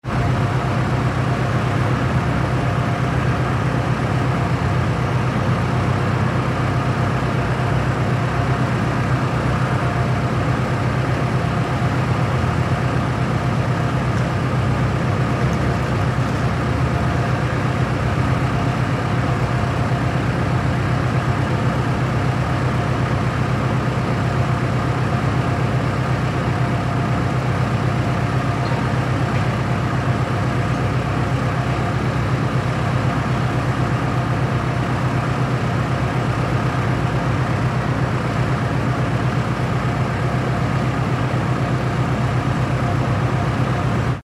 Motor de una excavadora
maquinaria
motor
Sonidos: Industria